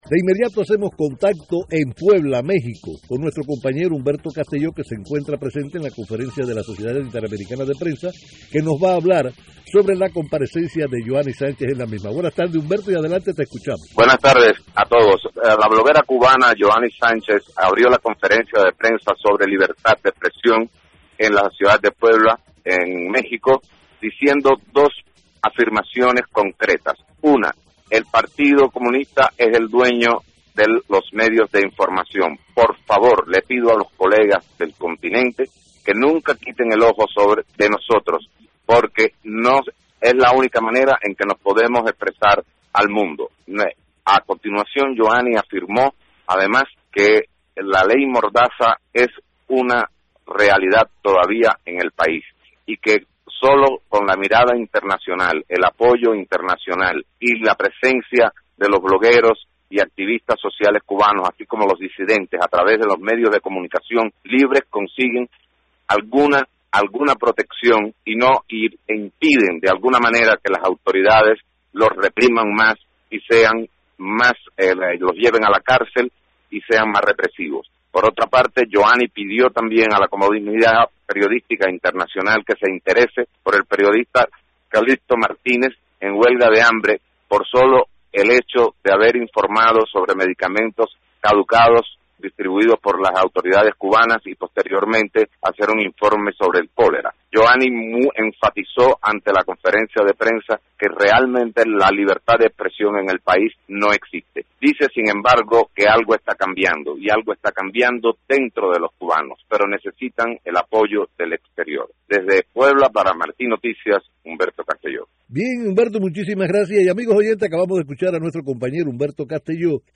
resume desde Puebla los puntos destacados del informe de Yoani.